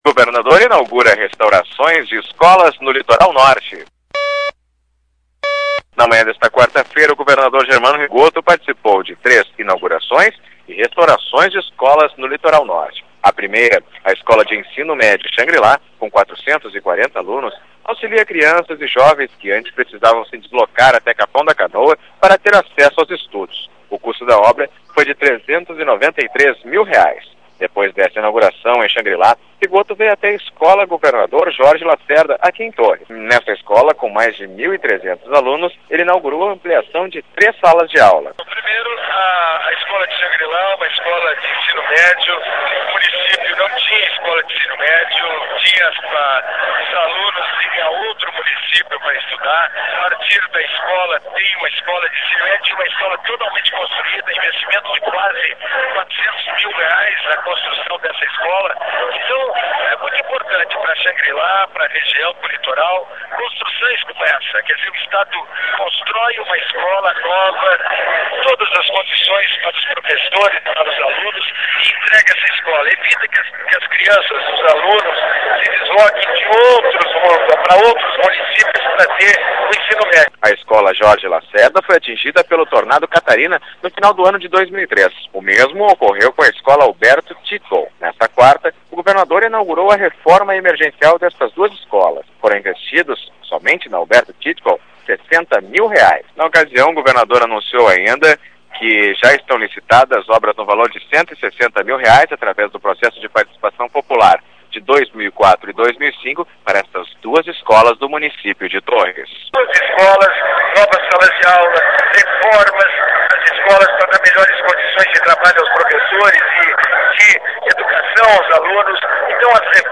O governador Germano Rigotto esteve nesta quarta-feira em municípios do litoral norte gaúcho onde participou da inauguração e da entrega de obras de reestruturação de escolas estaduais na região. Sonora: governador do Estado, Germano RigottoLocal: Torr